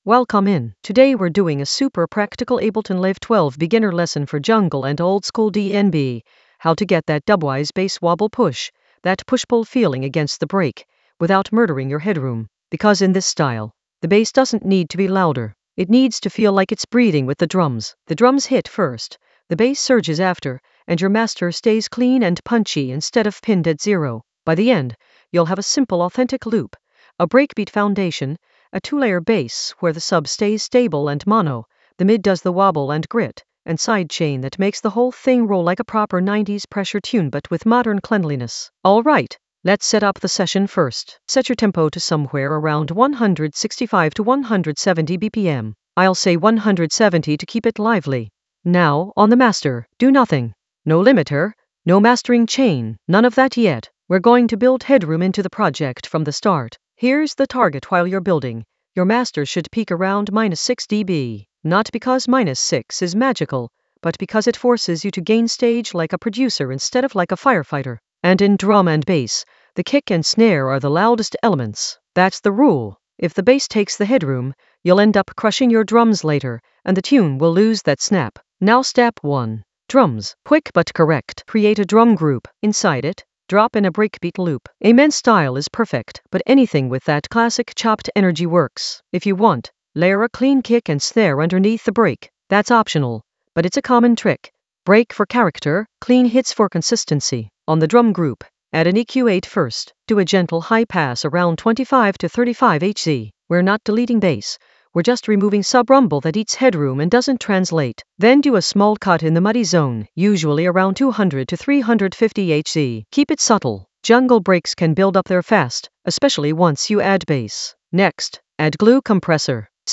Narrated lesson audio
The voice track includes the tutorial plus extra teacher commentary.
An AI-generated beginner Ableton lesson focused on Dubwise: bass wobble push without losing headroom in Ableton Live 12 for jungle oldskool DnB vibes in the Drums area of drum and bass production.